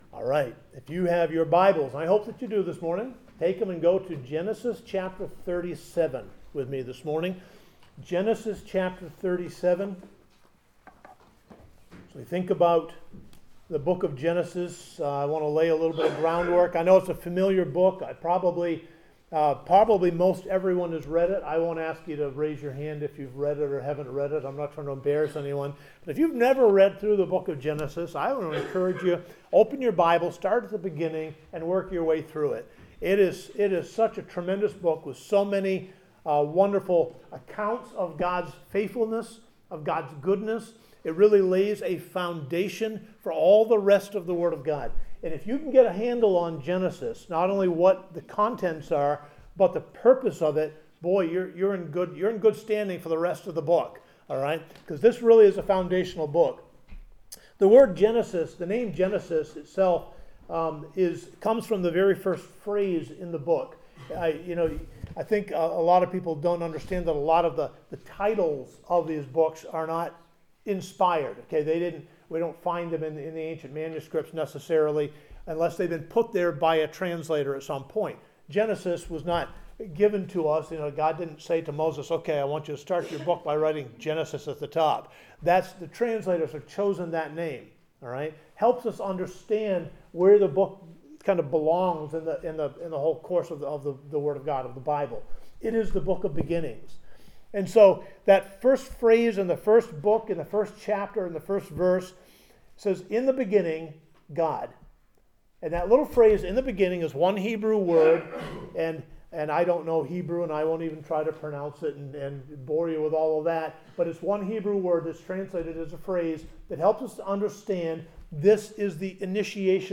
March 2026 Genesis Sunday Morning Joseph had been given With God's Promise.